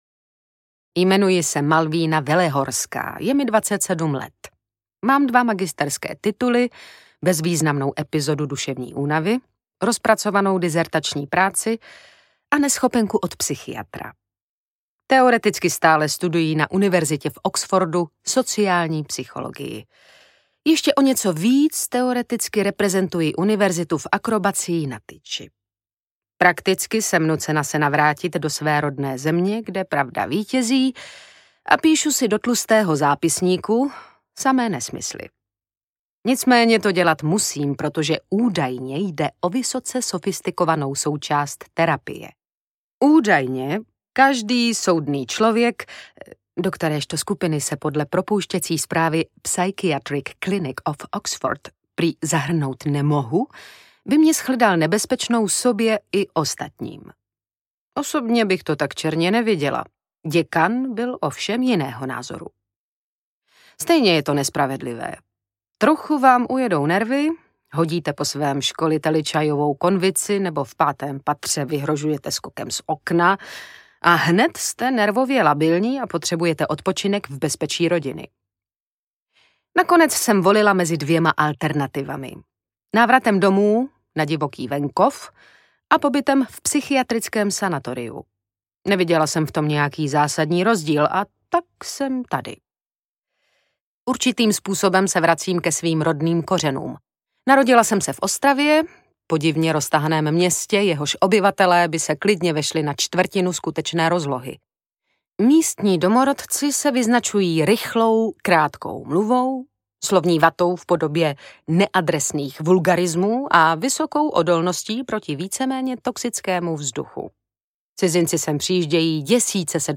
Na divokém venkově audiokniha
Ukázka z knihy
Čte Tereza Bebarová.
Zvukové předěly Soundguru.
Vyrobilo studio Soundguru.
• InterpretTereza Bebarová